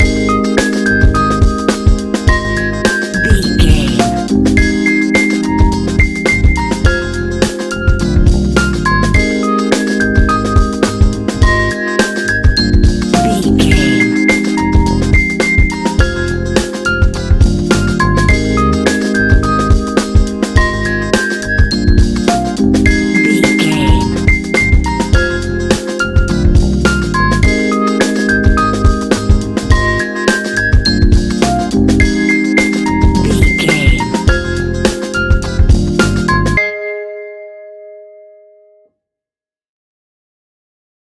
Ionian/Major
G♯
chilled
laid back
Lounge
sparse
new age
chilled electronica
ambient
atmospheric
morphing
instrumentals